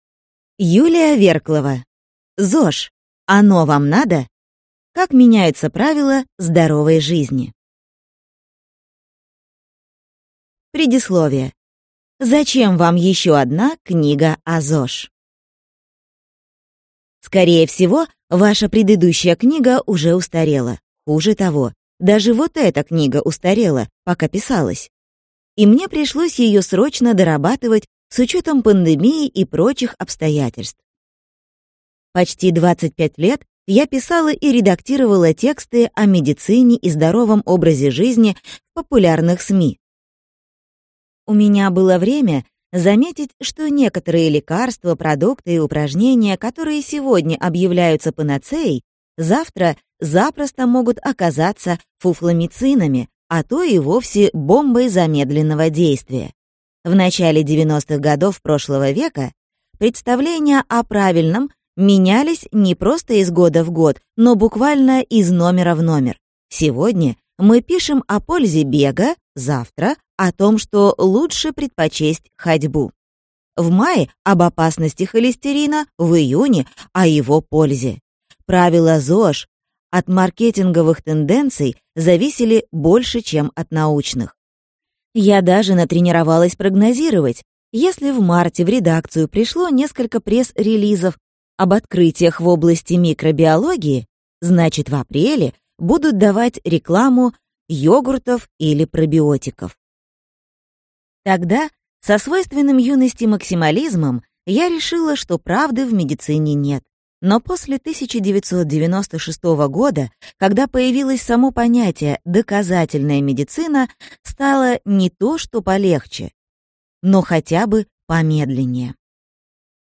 Аудиокнига ЗОЖ: оно вам надо?